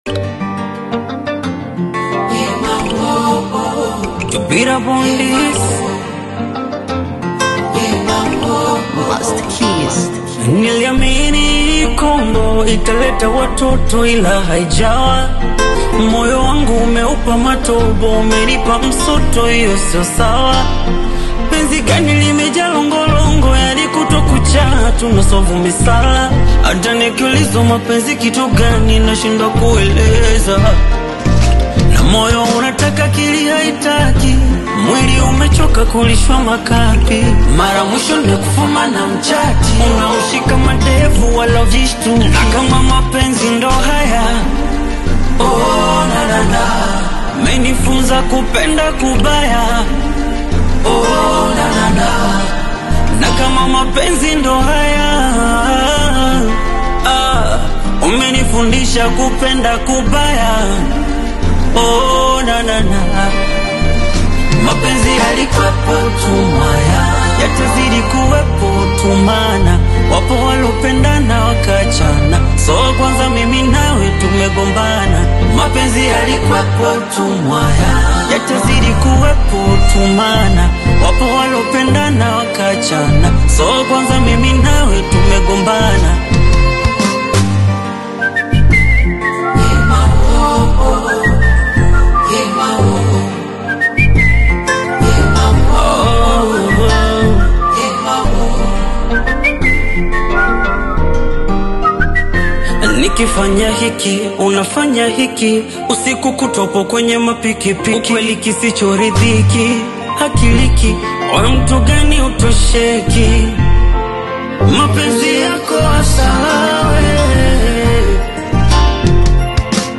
an emotional and reflective new single
smooth Bongo Flava melodies with heartfelt lyrics